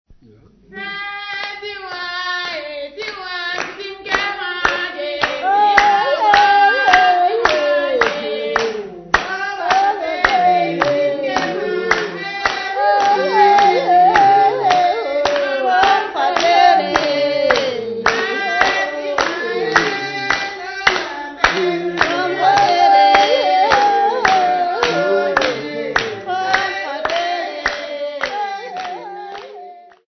Group Composition
Folk music--Africa
Field recordings
sound recording-musical
Indigenous music.
3.75ips reel